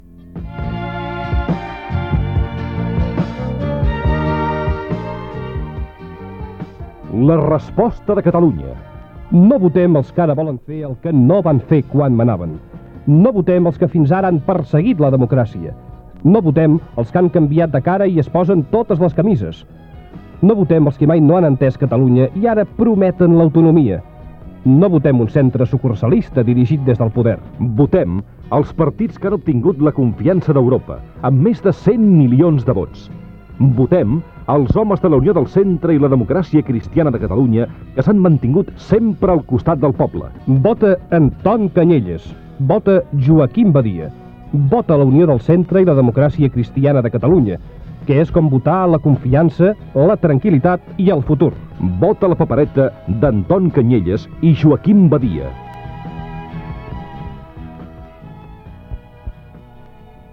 Propaganda electoral de la Unió del Centre i la Democràcia Cristiana de Catalunya a les eleccions generals del 15 de juny de 1977